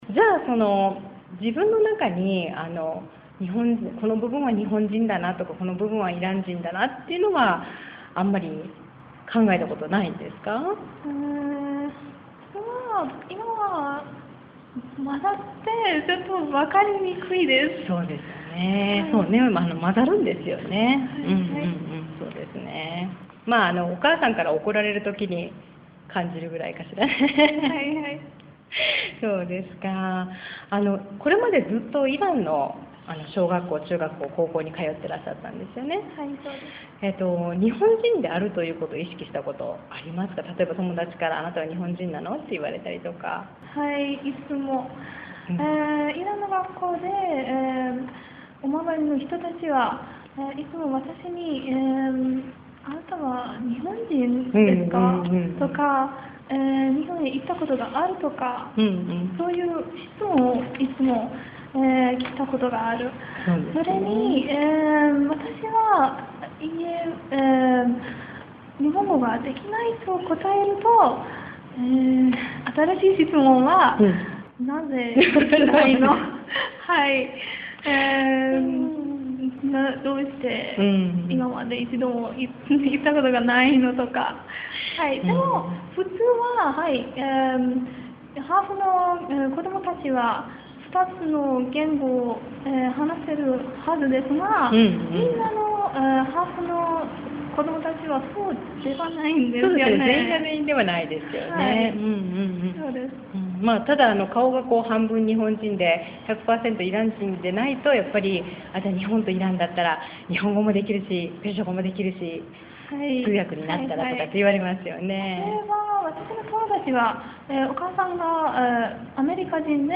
インタビューコーナー